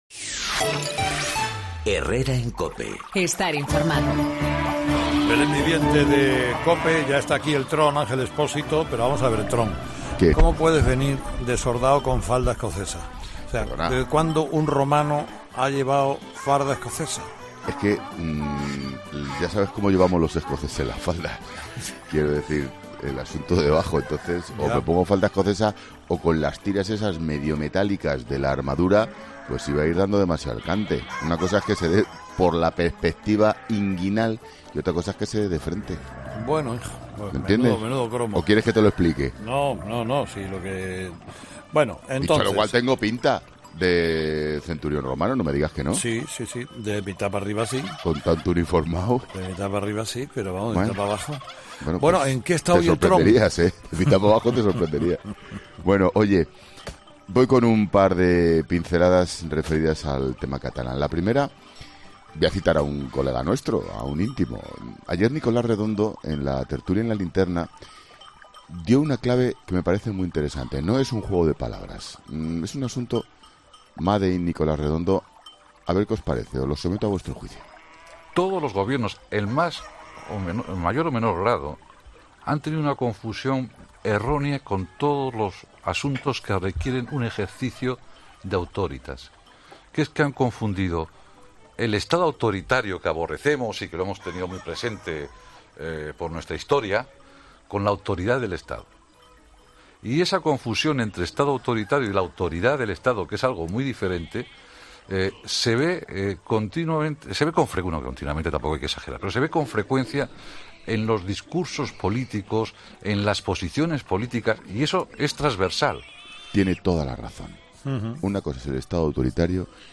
Escucha ahora 'El Paseíllo del Tron' con Ángel Expósito emitido este 19 de diciembre de 2018, en ‘Herrera en COPE’